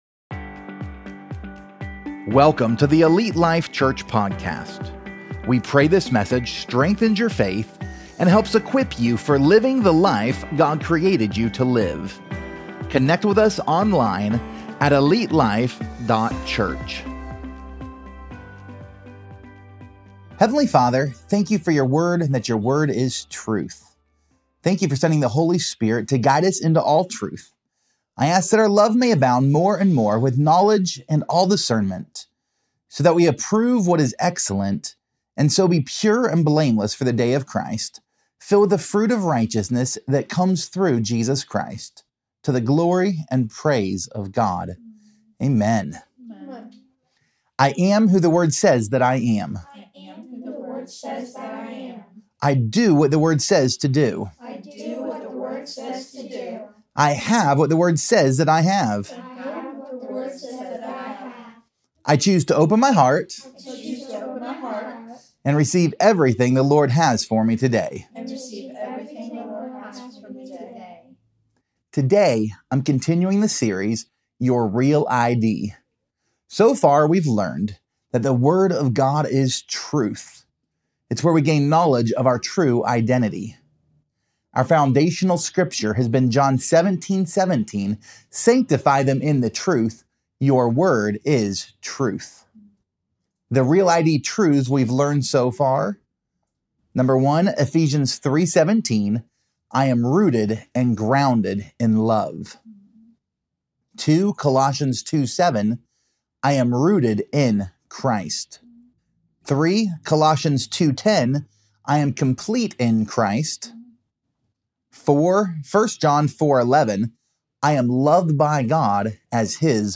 Pt 10: Wanted! | Your REAL ID Sermon Series